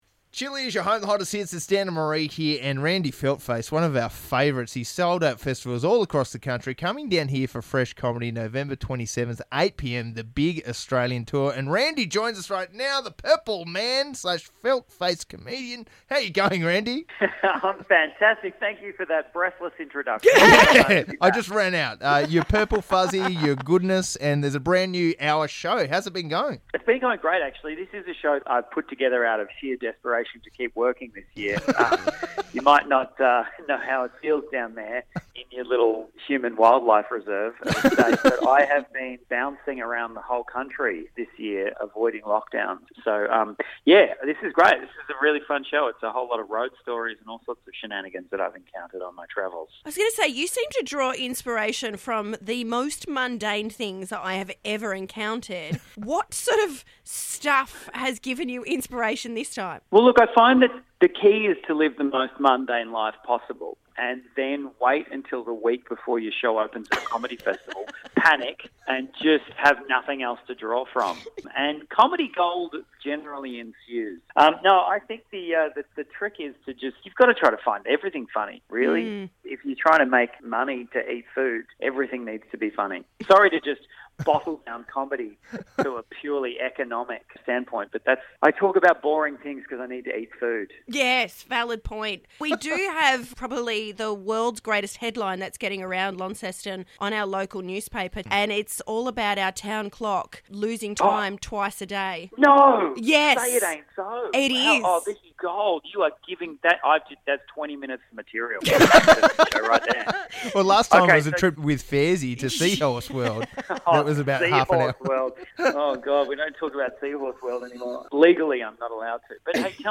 It's always a laugh when we chat with this handsome puppet.